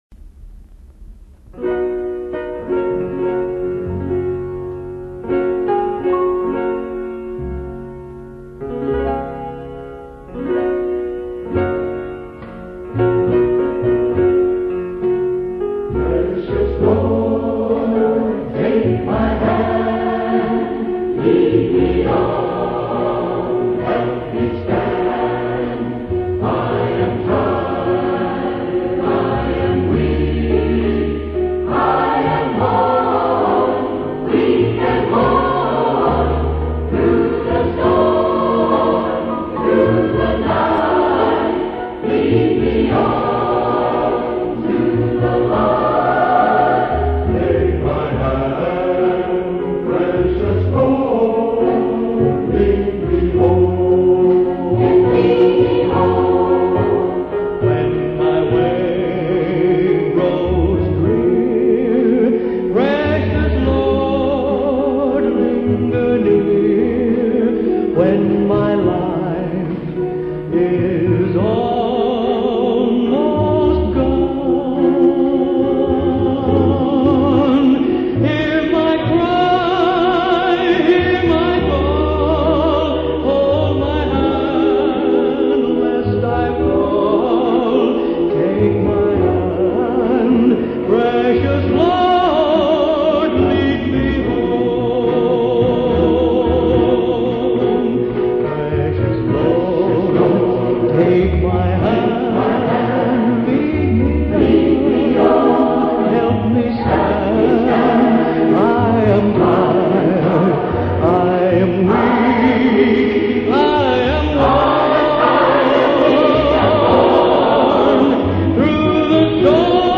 주님여  이 손을 꼭 잡고 가소서 (곡을 쓰게 된 배경)-영어 찬양- 합창 [파일 wma]